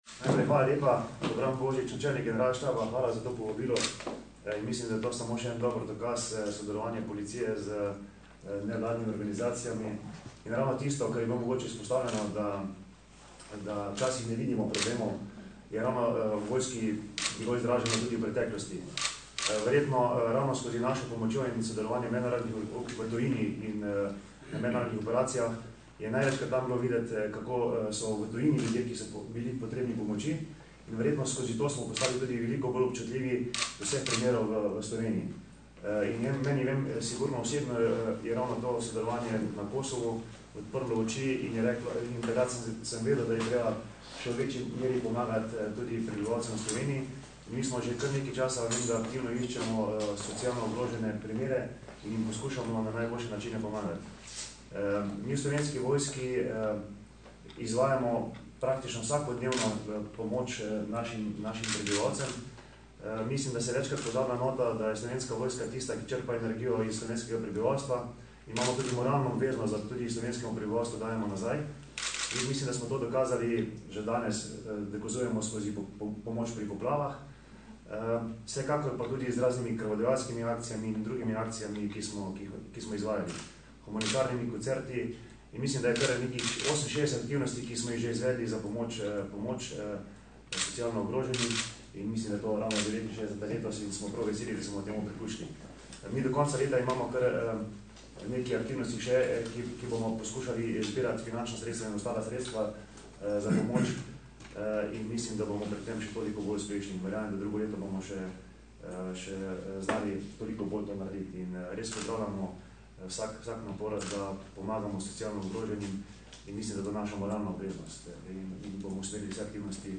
Sodelovanje smo predstavili na današnji novinarski konferenci.
Zvočni posnetek izjave Dobrana Božiča (mp3)